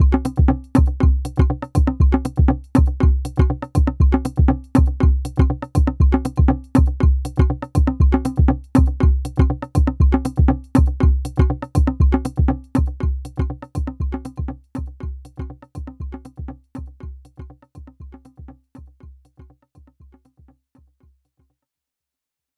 1 x Pattern, 8 x SY Toy, unmuted one track after another, then drove everything into reverb and delay using control all - it’s a bit of a muddy mess but I decided to toss it out anyway ;p
The above snippet had been recorded in mono accidentally, so here’s a stereo recording: